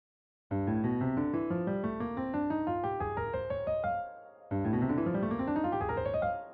Jacob Collier LOCRIAN scale/mode idea. although it is more like a “Mixolydian” modulating ascension.
meta-locrian-2.wav